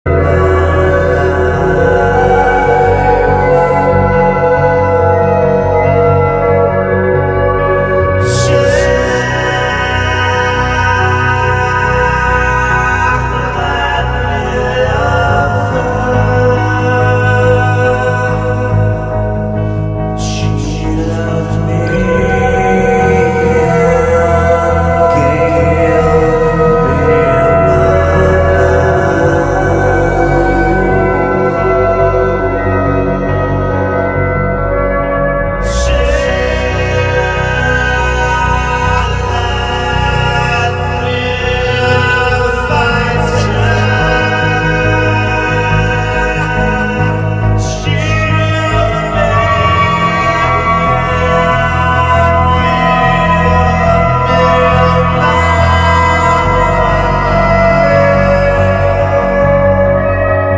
Post Rock, Experimental Rock >